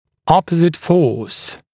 [‘ɔpəzɪt fɔːs][‘опэзит фо:с]противоположная сила